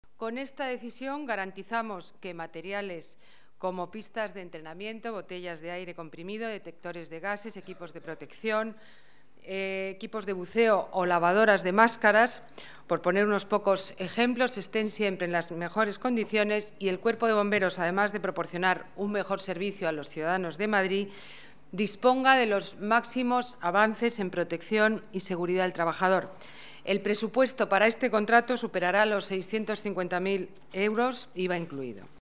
Nueva ventana:Declaraciones de la alcaldesa de Madrid, Ana Botella